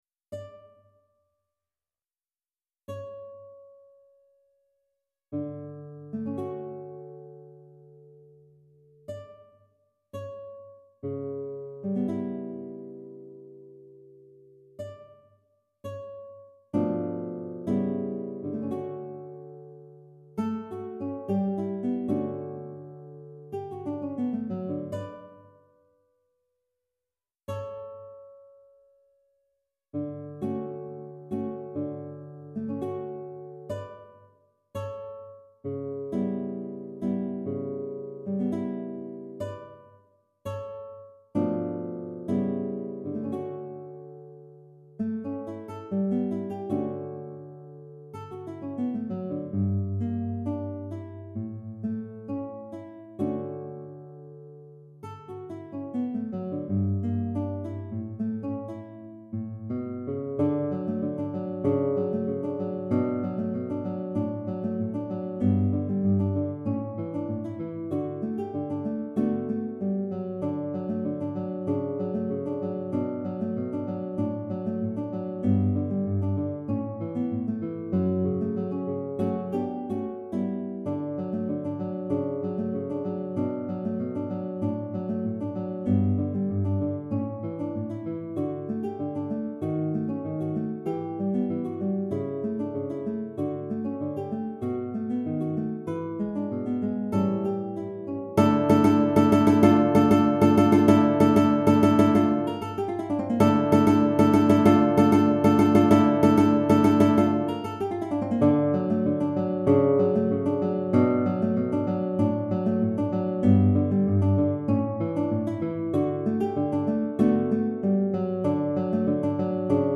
Répertoire pour Guitare